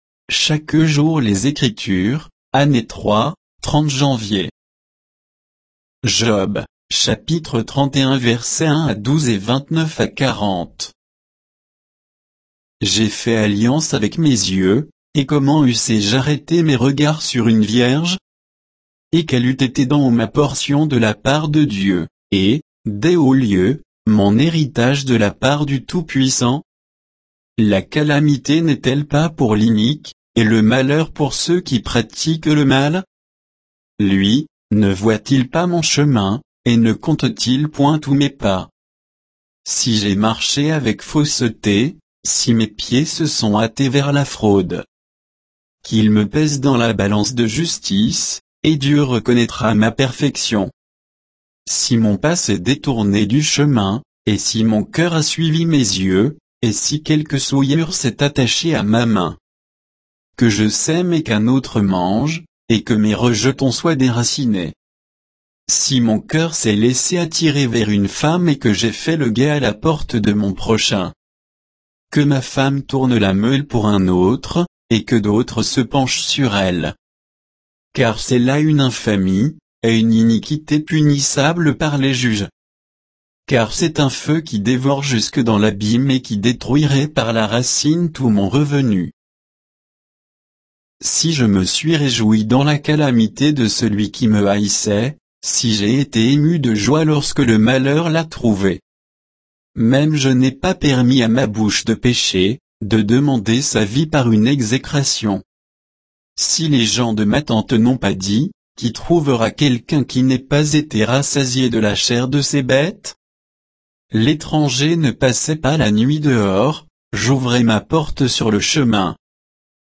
Méditation quoditienne de Chaque jour les Écritures sur Job 31, 1 à 12, 29 à 40